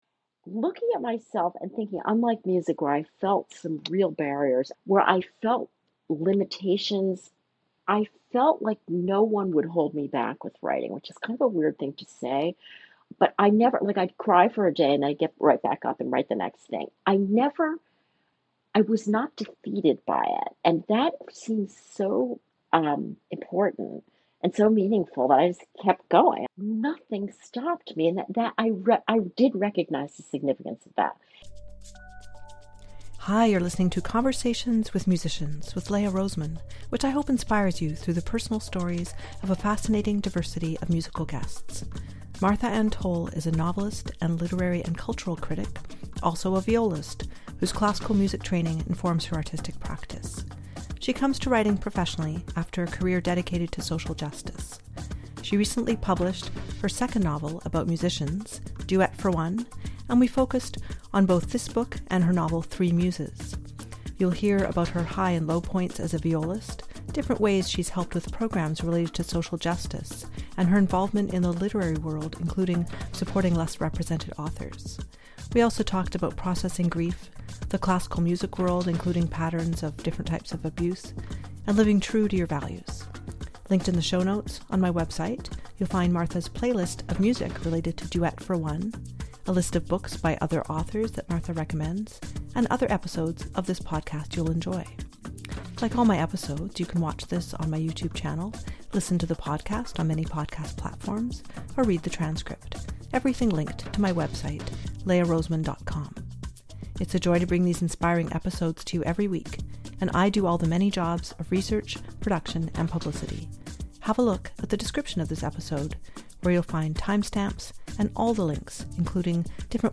There’s a fascinating variety to a life in music; this series features wonderful musicians worldwide with in-depth conversations and great music. Many episodes feature guests playing music spontaneously as part of the episode or sharing performances and albums.